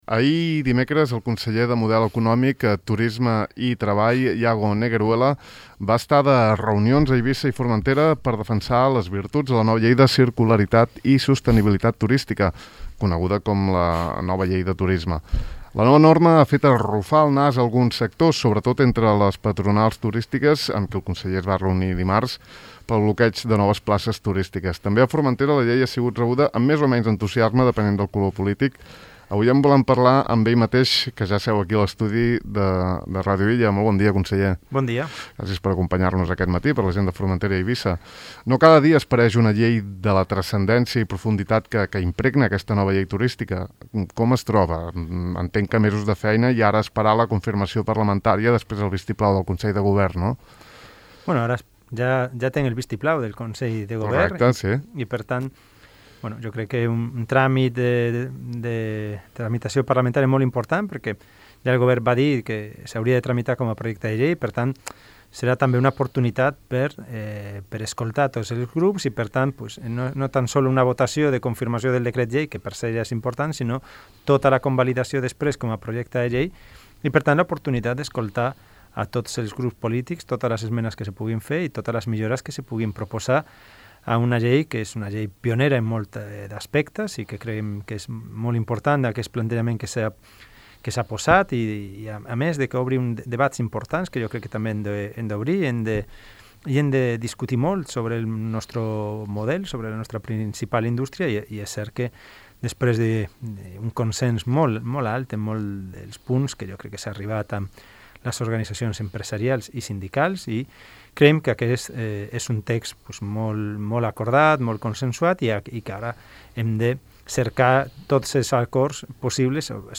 Avui n’hem parlat amb ell mateix a l’estudi 1 de Ràdio Illa.